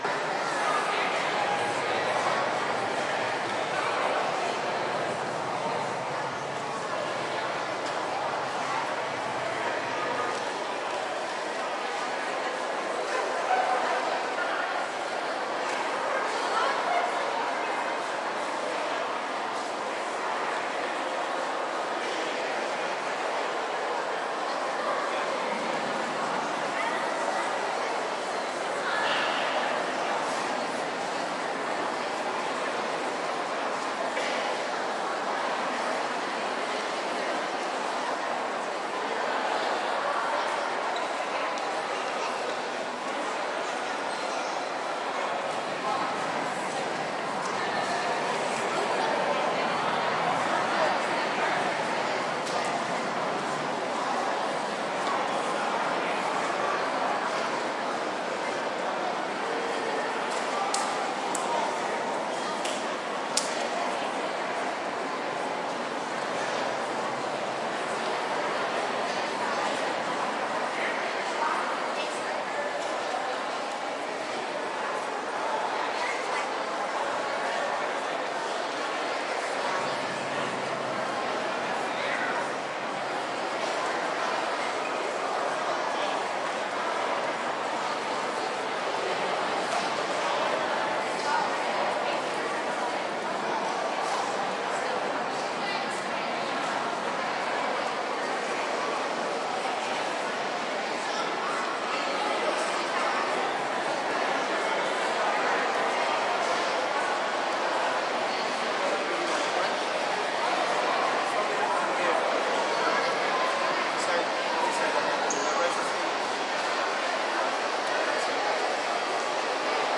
纽约市 " 人群中的大型博物馆空间 古根海姆2 美国纽约市
描述：人群int大博物馆空间Guggenheim2 NYC，USA.flac
Tag: 古根海姆博物馆 纽约市 博物馆 INT 空间 人群 美国